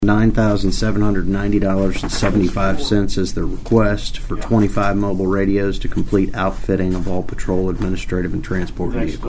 Commissioner Mitch Antle